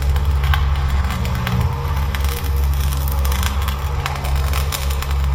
sounds / monsters / poltergeist / ~tele_idle_0.ogg